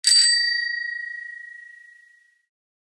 bicycle-bell_07
bell bells bicycle bike bright chime chimes clang sound effect free sound royalty free Memes